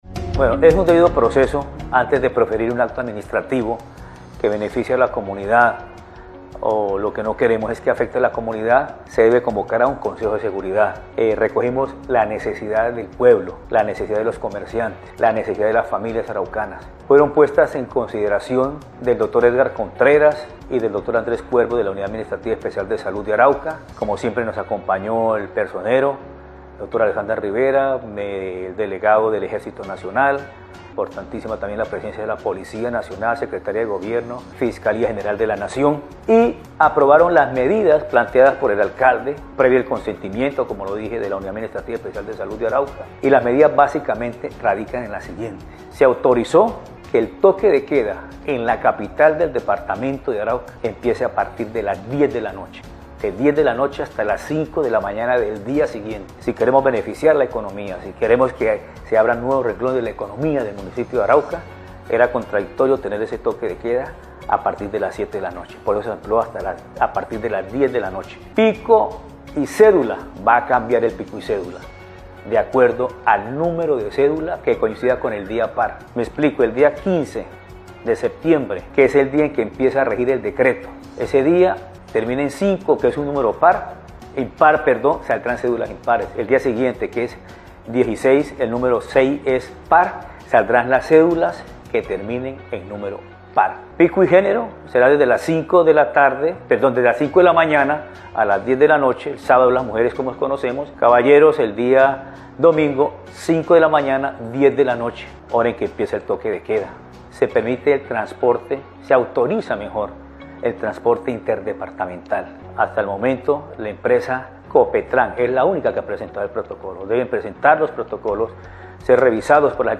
AUDIO: Edgar Fernando Tovar Pedraza, Alcalde de Arauca